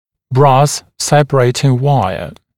[brɑːs ‘sepəreɪtɪŋ ‘waɪə][бра:с ‘сэпэрэйтин ‘уайэ]латунная сепарационная лигатура